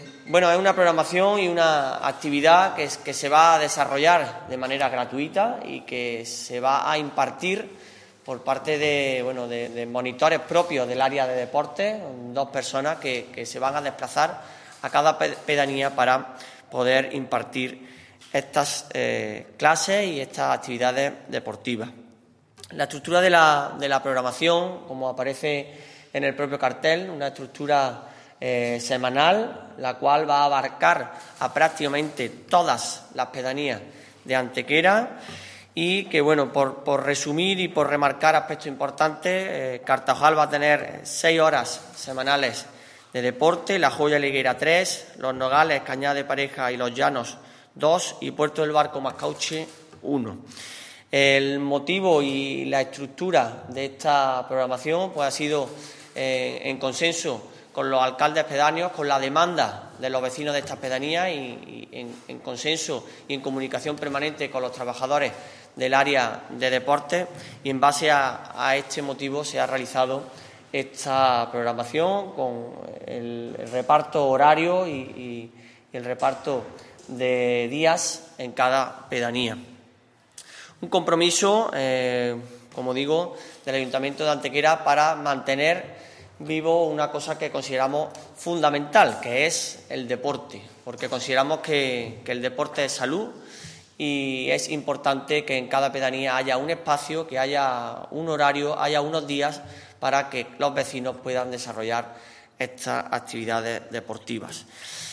El concejal delegado de Anejos y Desarrollo Rural, José Manuel Fernández, ha presentado hoy en rueda de prensa la nueva programación deportiva en las pedanías de Antequera, que tendrá carácter permanente hasta el próximo verano y se desarrollará semanalmente a partir del próximo lunes 2 de octubre.
Cortes de voz